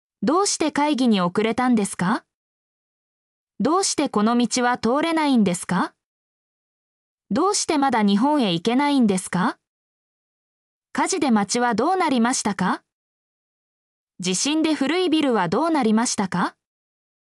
mp3-output-ttsfreedotcom-18_acuinOB8.mp3